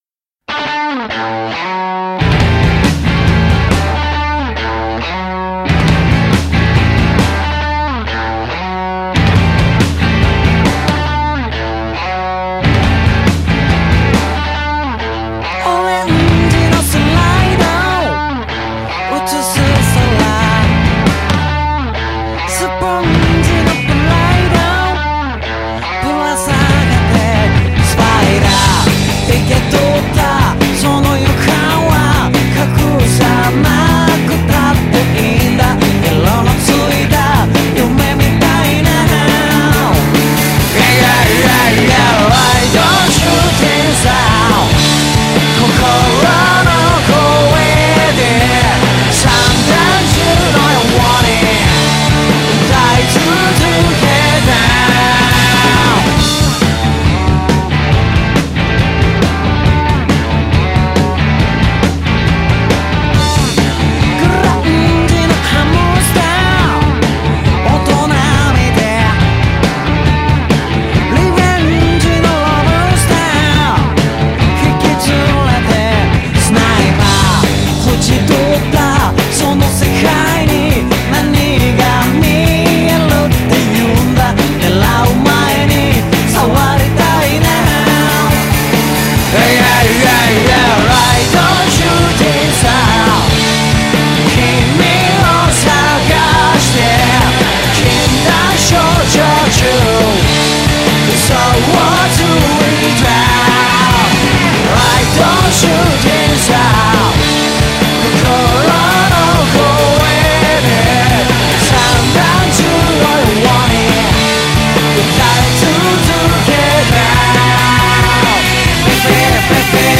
BPM69-138
Audio QualityPerfect (High Quality)